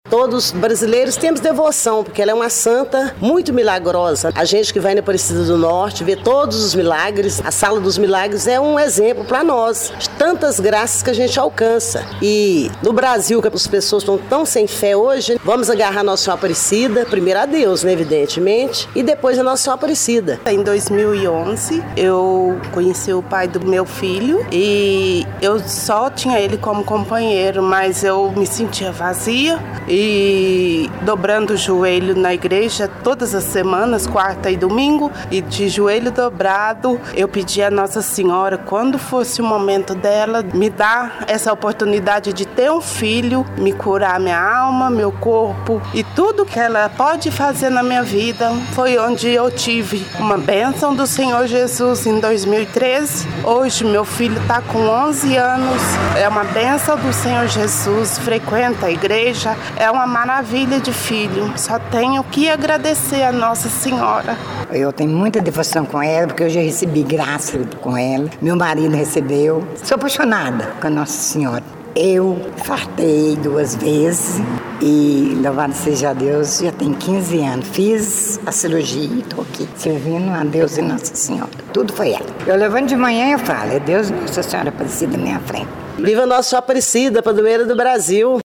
O JM conversou com algumas pessoas que, diante da imagem da padroeira, fizeram questão de registrar seus testemunhos.
Devotos